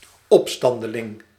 Ääntäminen
IPA : /ˈɹɛbəl/